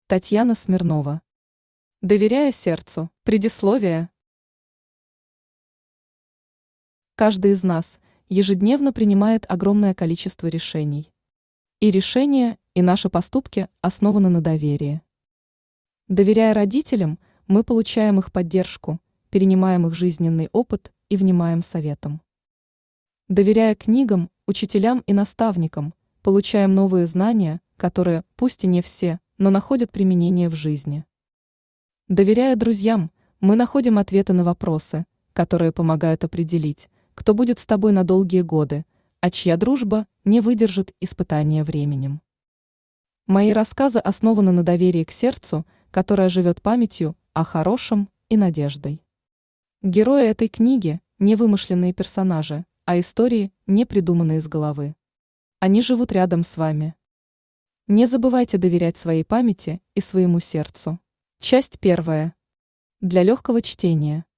Aудиокнига Доверяя сердцу Автор Татьяна Смирнова Читает аудиокнигу Авточтец ЛитРес.